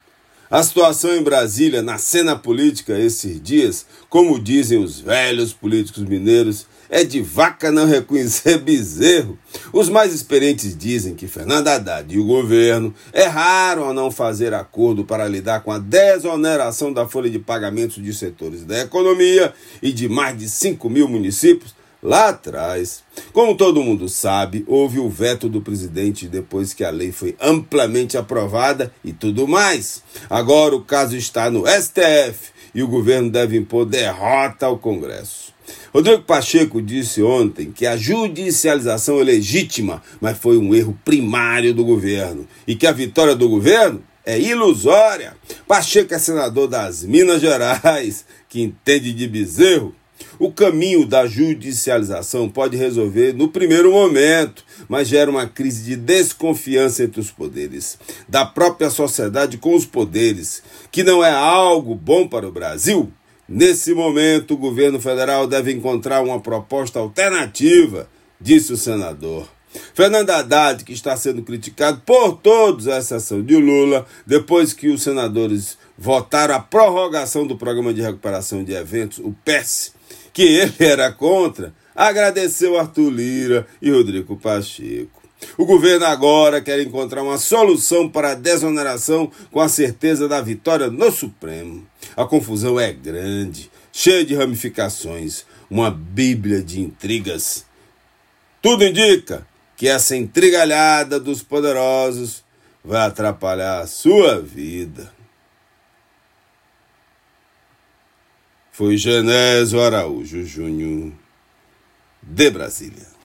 Comentário
direto de Brasília